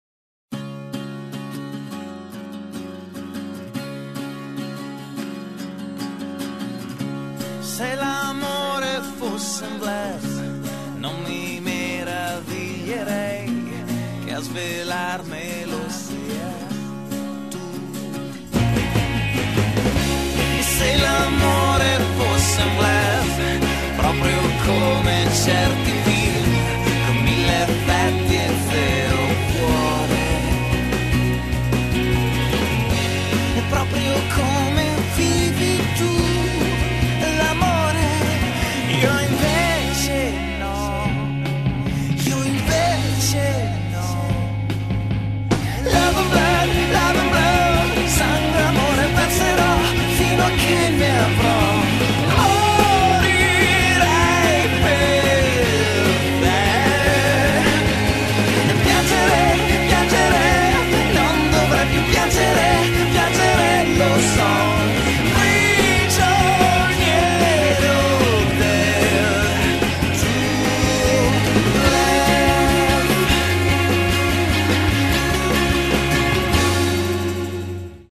L' intro di chitarra è stato trasmesso